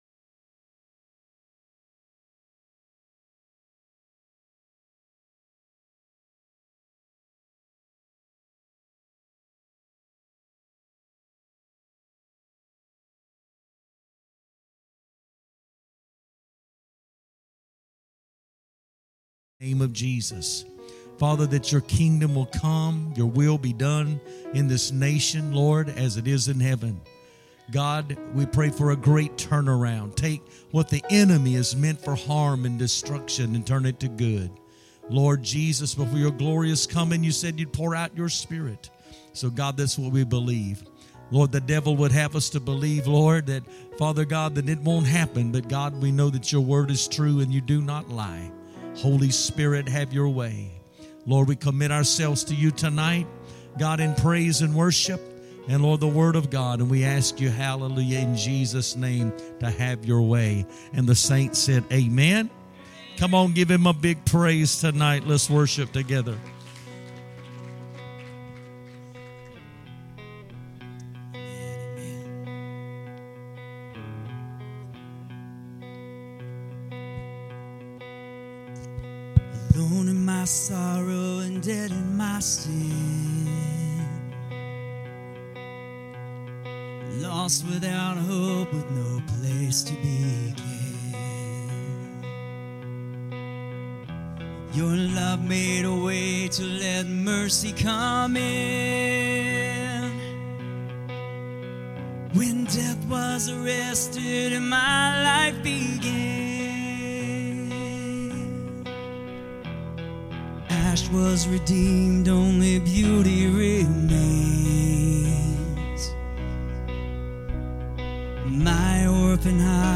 Sunday Morning Service. Wednesday Service.